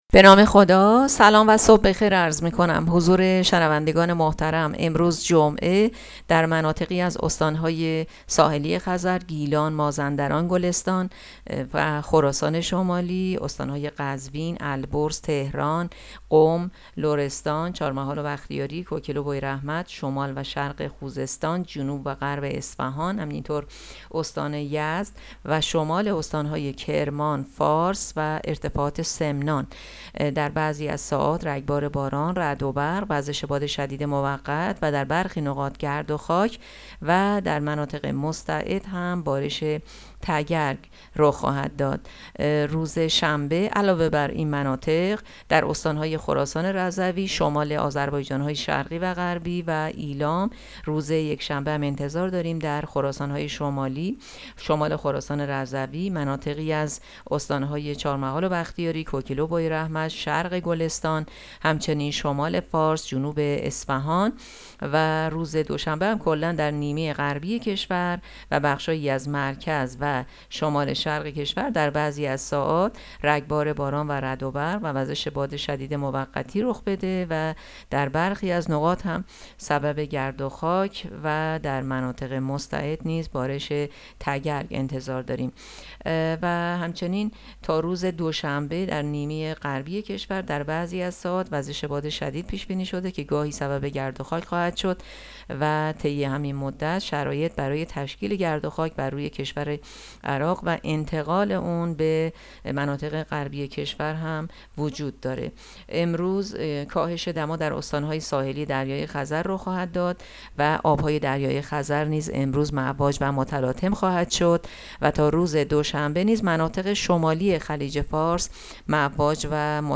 گزارش رادیو اینترنتی پایگاه‌ خبری از آخرین وضعیت آب‌وهوای ۲۲ فروردین؛